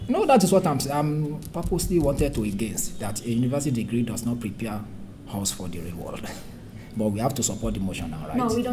S1 = Bruneian female S3 = Nigerian male Context: They are discussing the motion for the debate that they are preparing for.
Intended Word: us Heard as: house Discussion: S3 begins this word with [h].